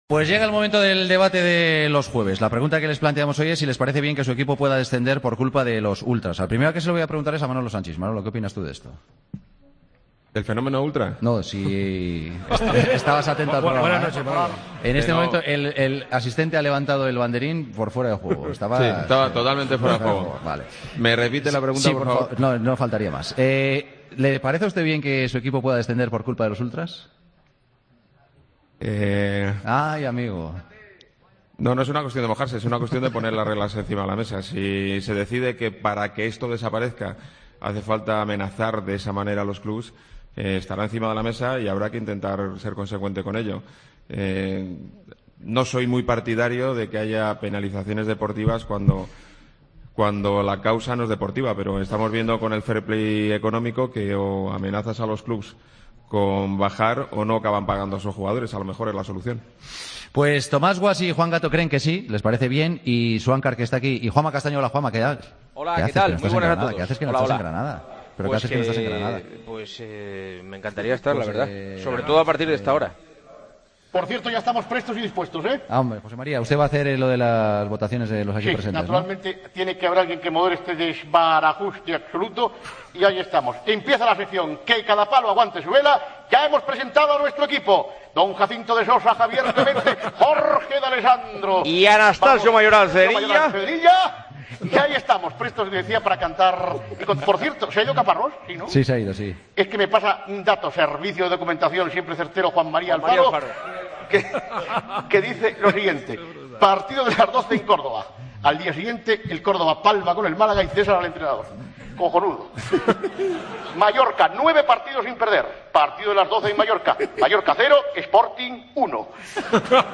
El debate de los jueves: ¿Te parece bien que tu equipo pueda descender por culpa de los ultras?